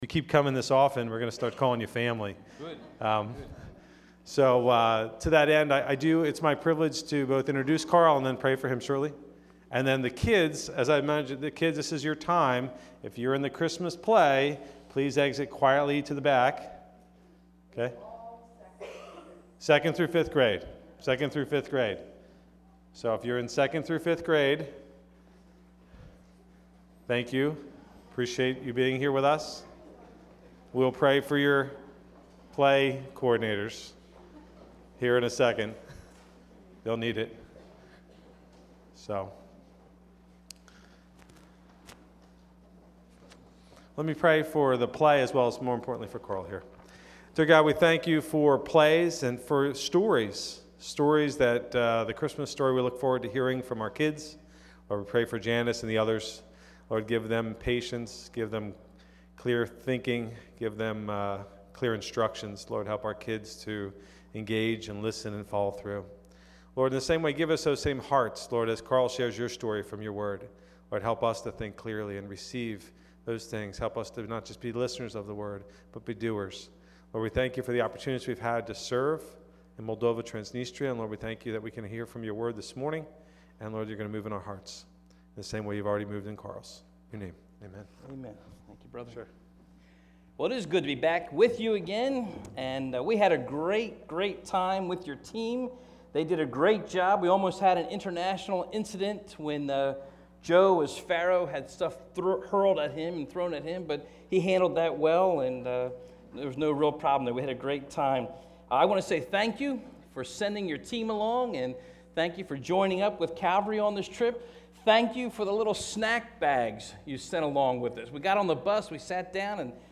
Occasion: Mission Sunday